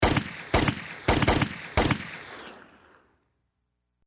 FX [Shots].mp3